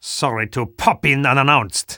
The Spy on hidden weaponry